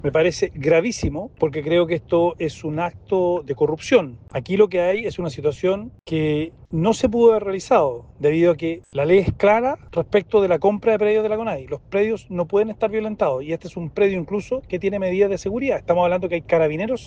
En tanto, el diputado y presidente de Amarillos por Chile, Andrés Jouannet, tachó la situación como un acto de corrupción.